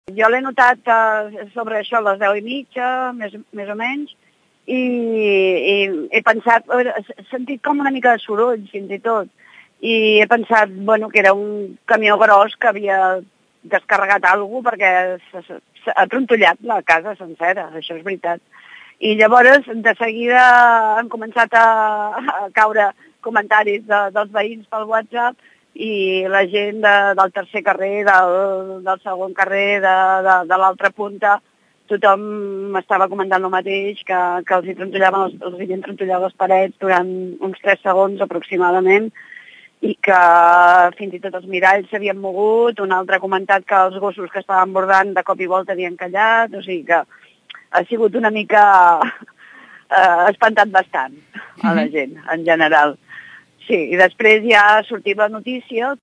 testimoni terratremol
testimoni-terratremol.mp3